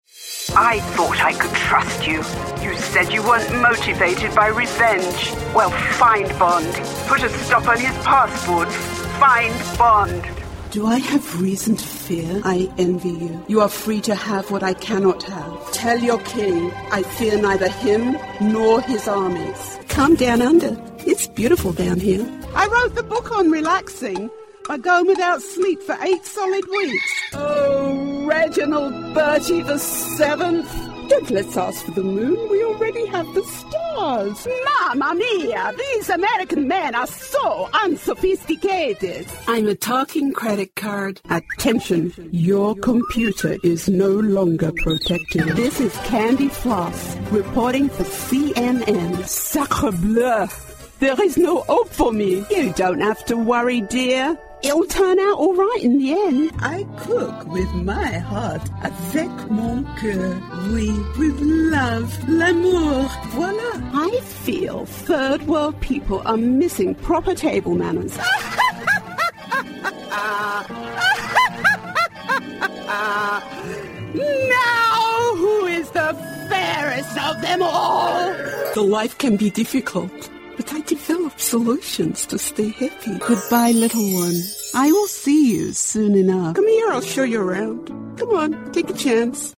Animación
I keep my delivery honest, believable, and authentic, and can also be humorous with a touch of irony and pizzazz when necessary.
TLM103 mic
ContraltoProfundoBajo
ConversacionalCálidoAmistosoGenuinoSofisticadoConfiableEntusiastaConfiadoConfidencialElocuenteInnovadorSerioCosmopolitaArtísticaCalmanteCreíble